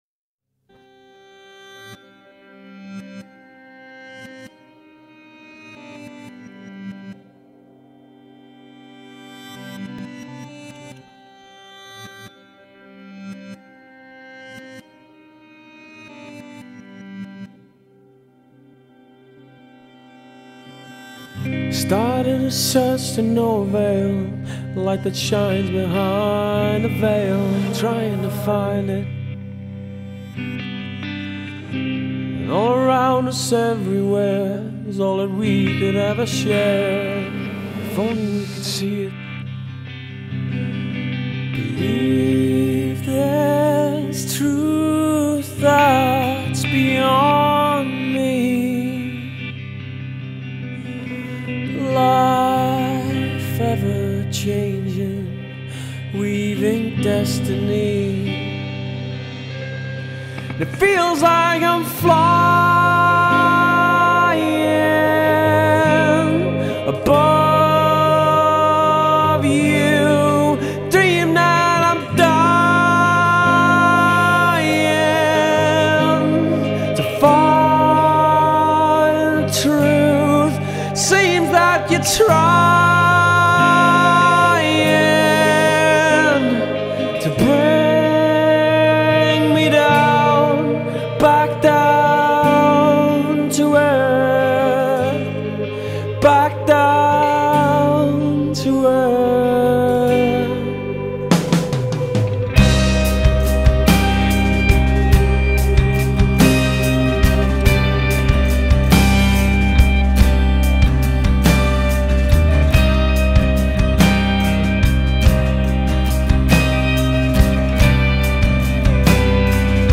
گیتارهای ملودیک، ریف‌های آرام، درام‌های نرم و وکال احساسی